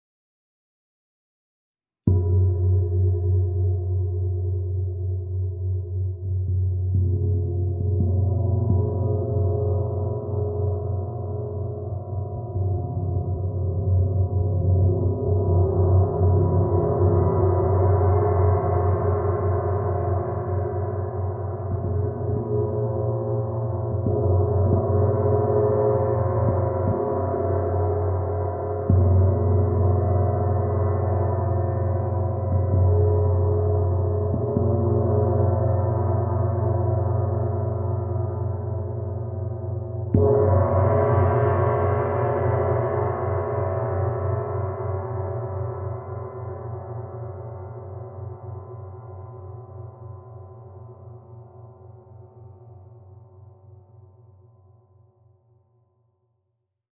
Meinl Sonic Energy 22" Soundscape Gong, Spiral (SGSP22)